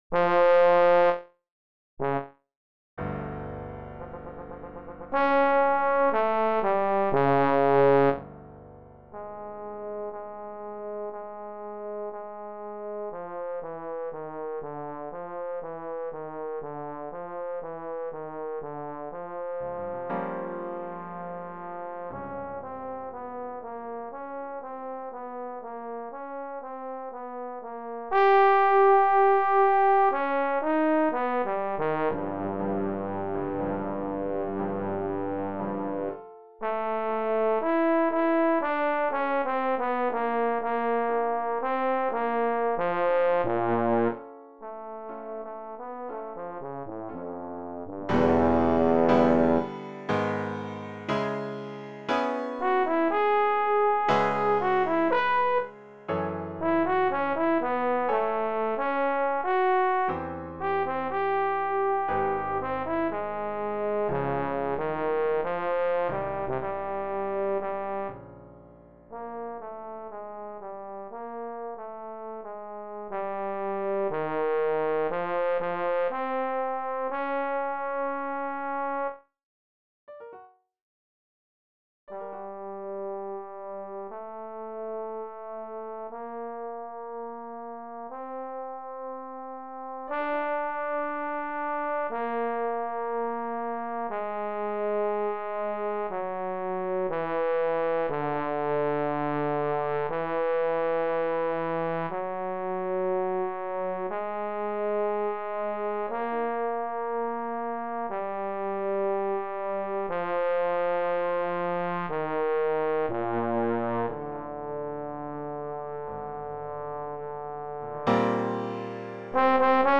Pour trombone et piano DEGRE FIN DE CYCLE 2 Durée